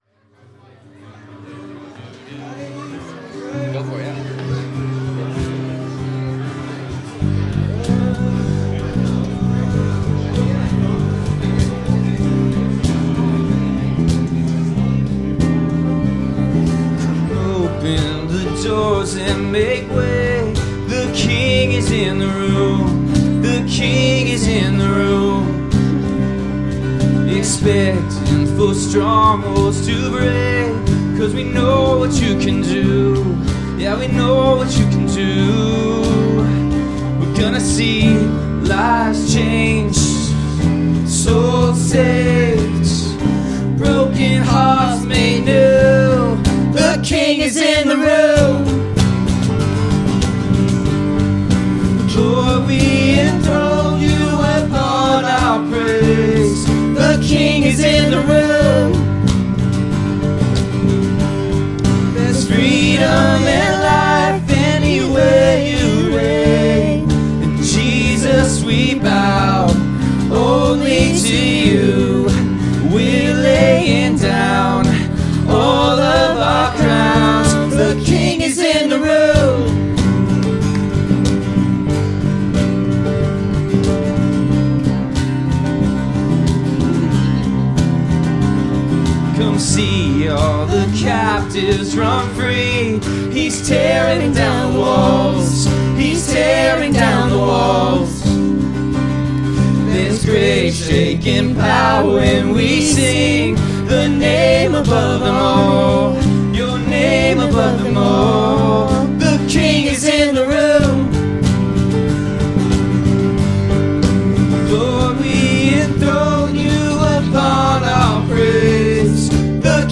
Worship 2025-08-03